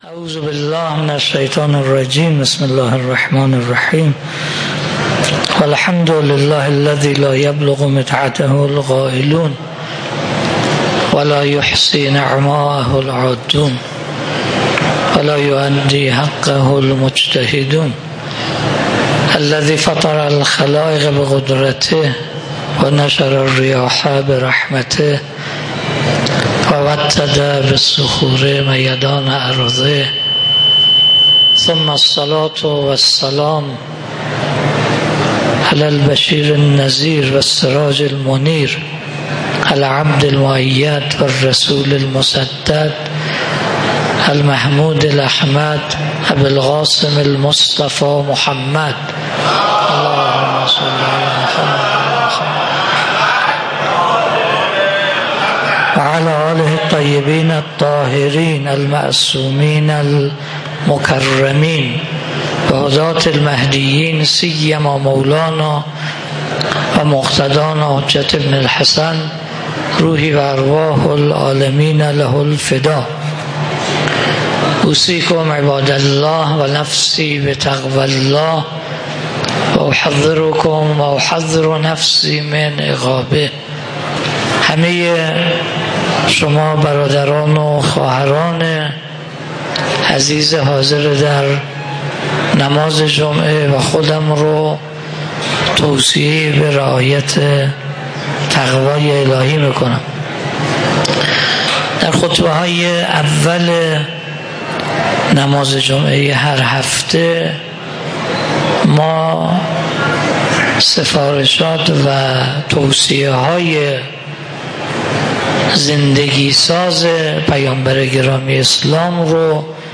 خطبه-اول-1.mp3